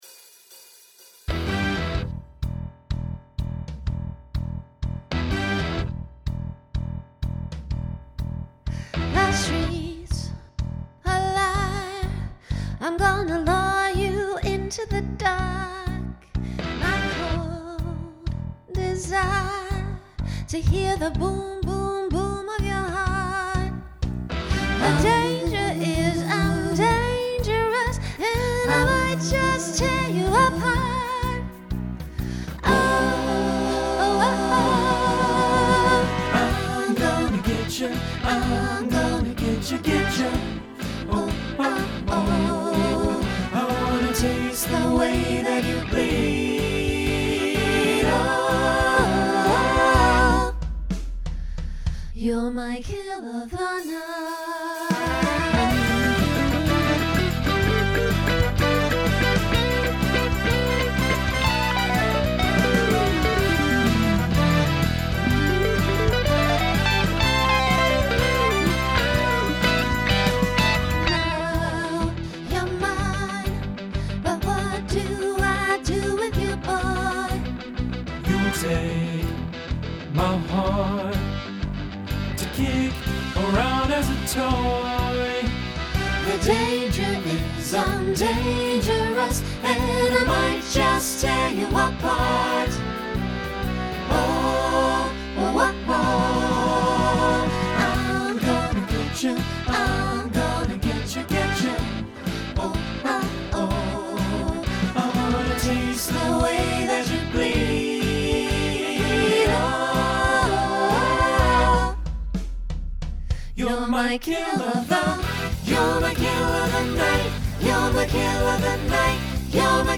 Genre Rock , Swing/Jazz
Voicing SATB